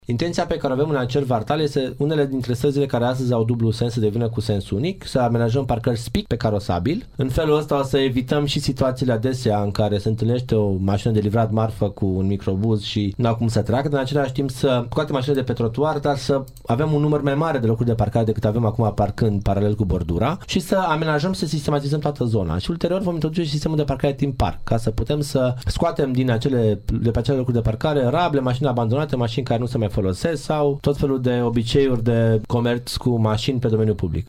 Viceprimarul Ruben Lațcău spune că următorul pas va fi instituirea unor sensuri unice în perimetrul străzilor Iris, Mureș, Hebe și Hărniciei.